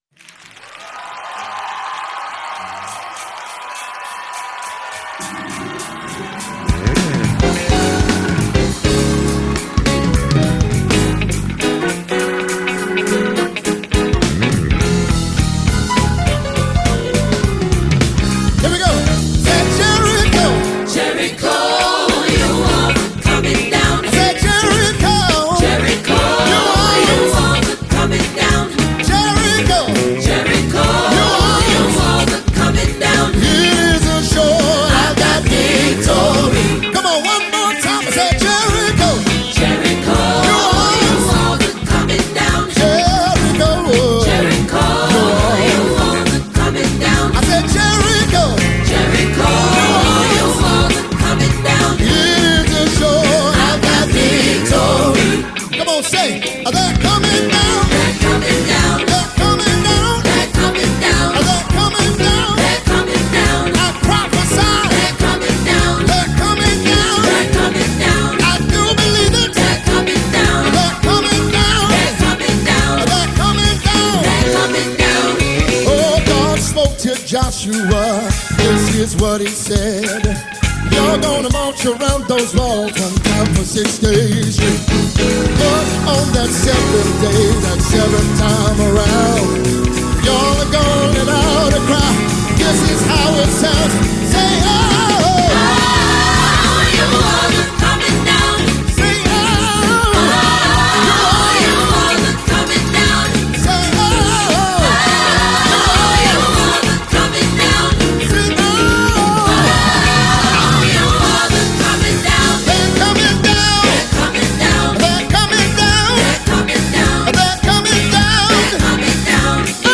Mp3 Gospel Songs
an American gospel singer, songwriter, and music pastor.
his sound flows, cadence, and delivery were superb.